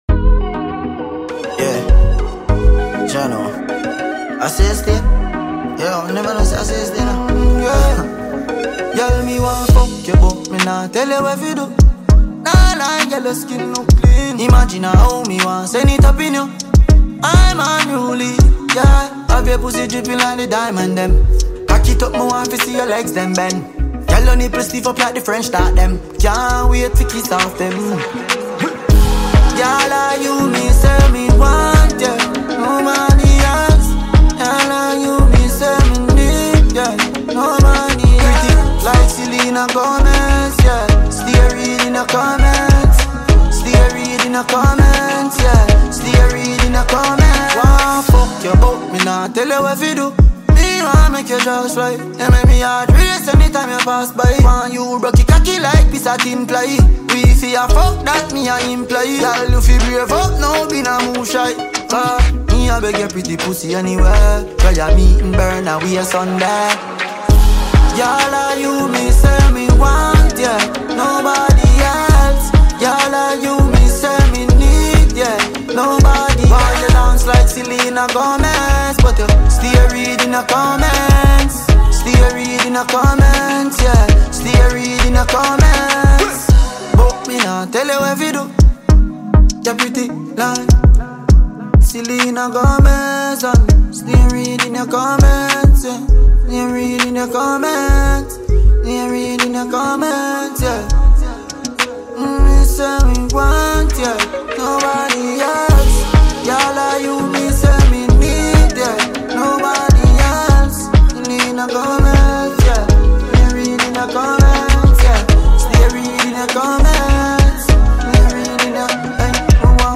Dancehall Music
steady, hard-hitting rhythm